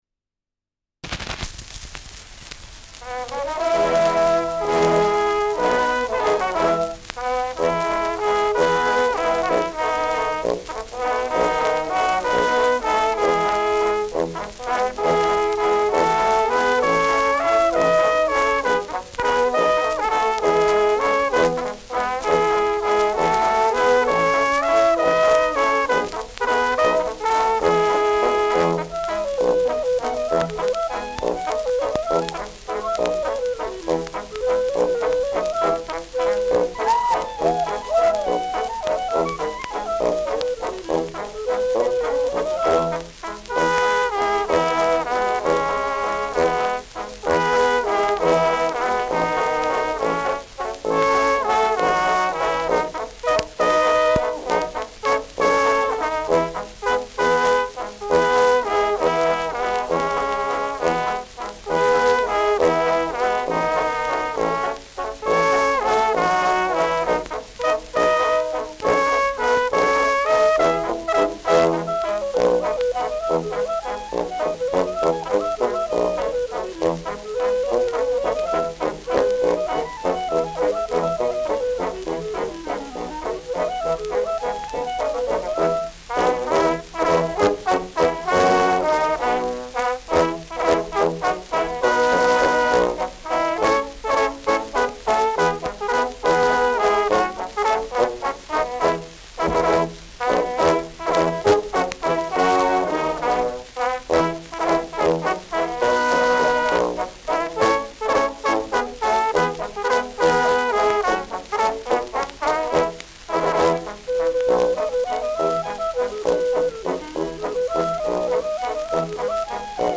Schellackplatte
präsentes Rauschen : leichtes Knacken
Mit Juchzern. Enthält bekannte Vierzeilermelodien.